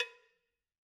4-cowbell.wav